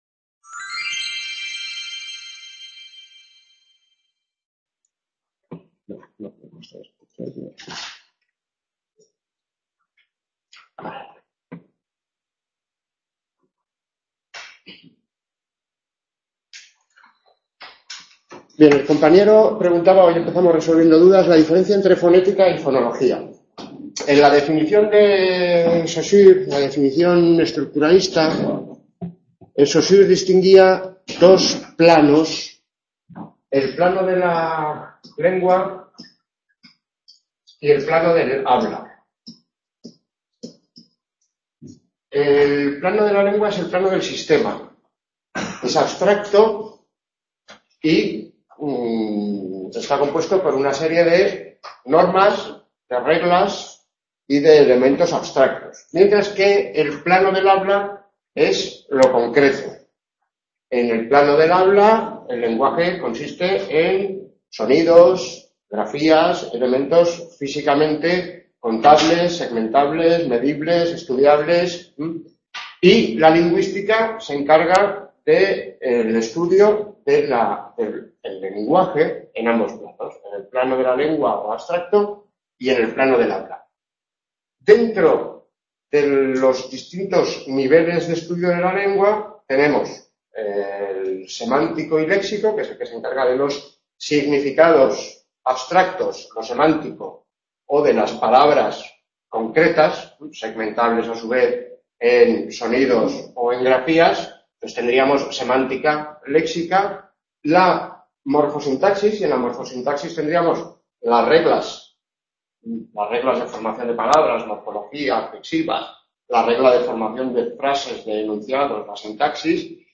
5. La expresión oral: errores y problemas de pronunciación en lengua española Description COELEI (Grados en estudios ingleses) y COEEI (Grado en lengua y literatura españolas) Tutorías del CA de Madrid - Gregorio Marañón, 16 de diciembre, 2013.